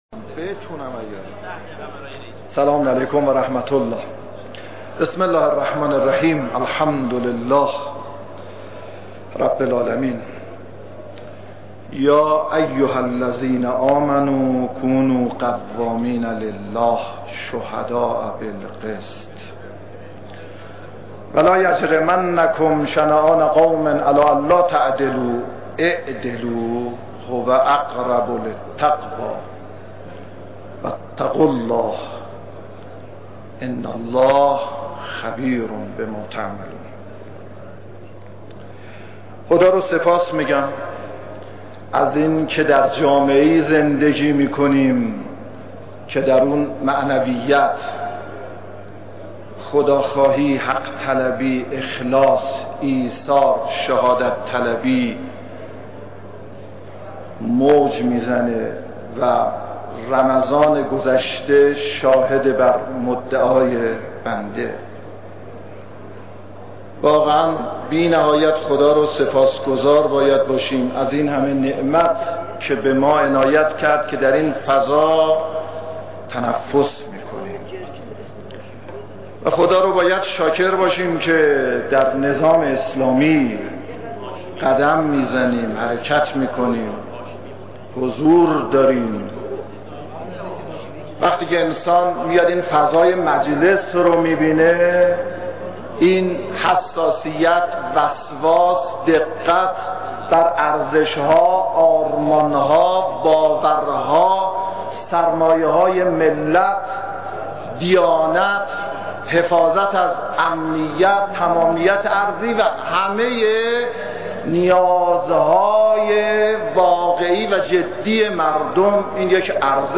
صوت/ دفاع پورمحمدی از عملکرد خود
وزیر پیشنهادی دادگستری در دفاعیات خود گفت: یکی از اقداماتی که باید با همکاری مجلس به آن بپردازیم، تصویب قانون وظایف وزارت دادگستری است تا مشکلات و خلأهای موجود برطرف شود.